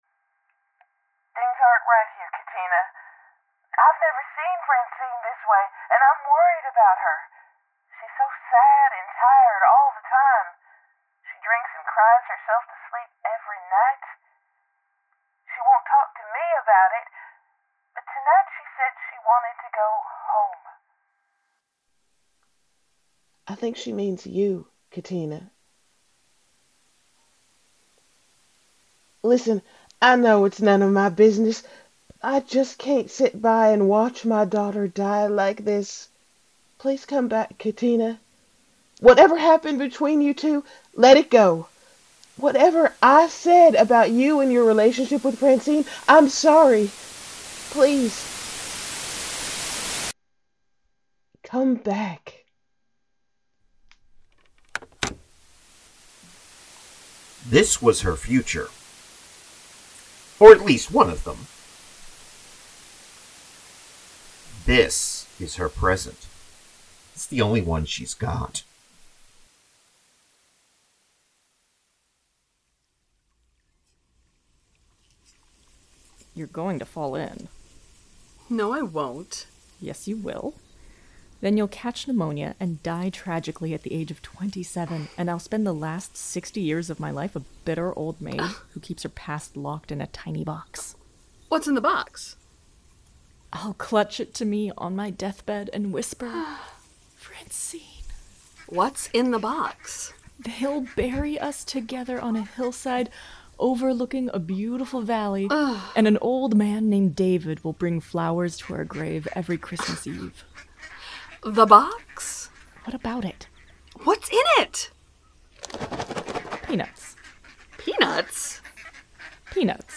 Strangers In Paradise – The Audio Drama – Book 7 – Episode 3 – Two True Freaks
The Ocadecagonagon Theater Group